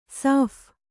♪ sāph